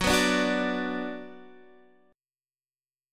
F#sus4#5 chord